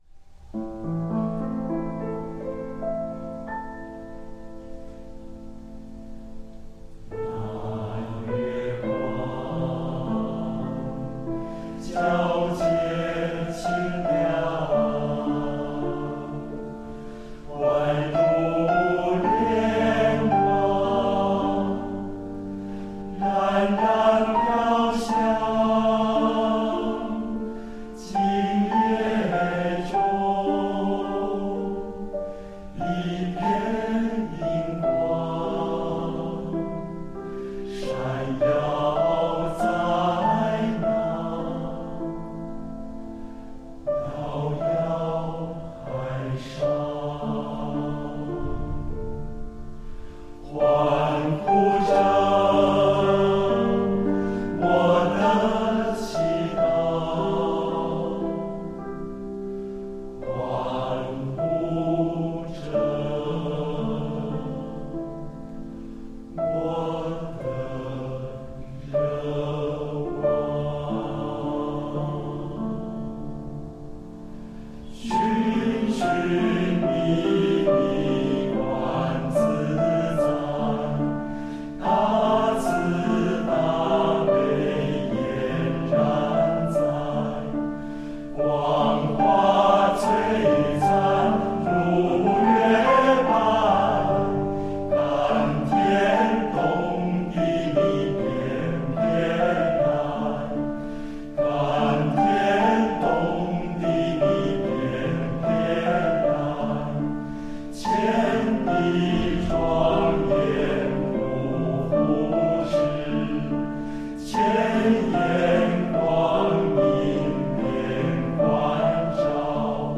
人聲音檔下載